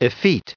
1755_effete.ogg